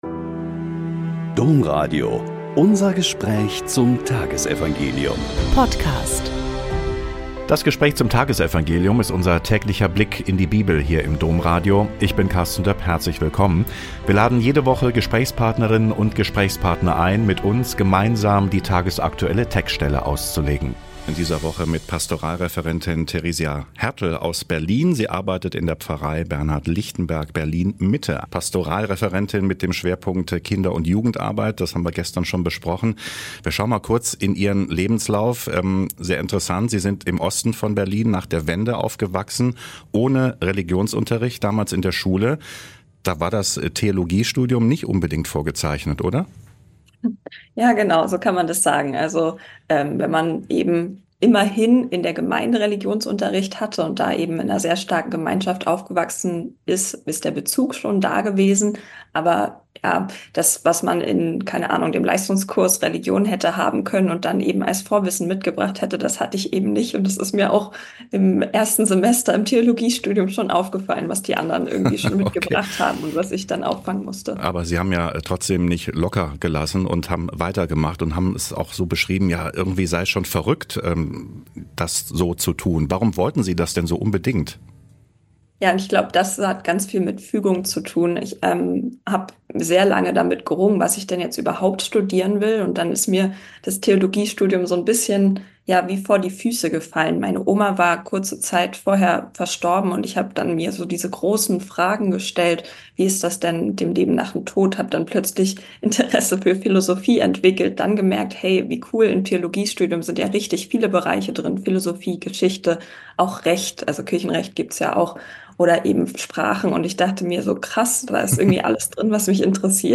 Lk 1,39-56 - Gespräch